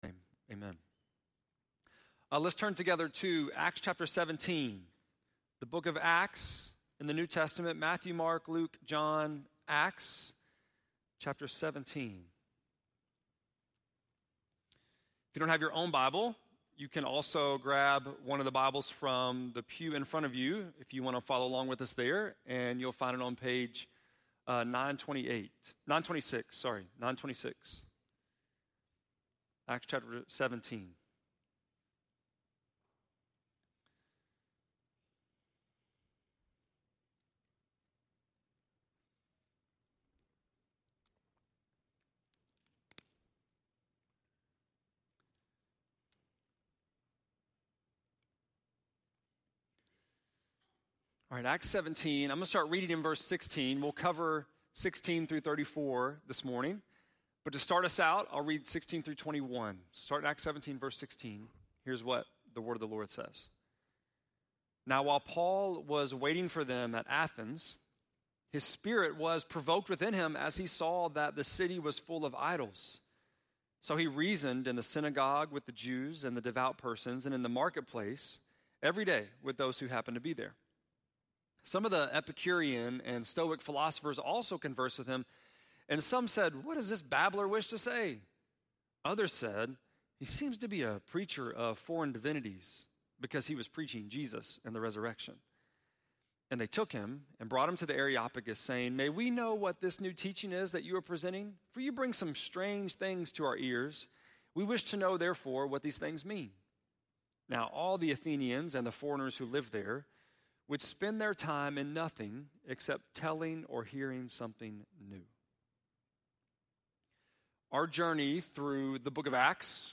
9.22-sermon.mp3